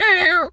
bird_tweety_hurt_05.wav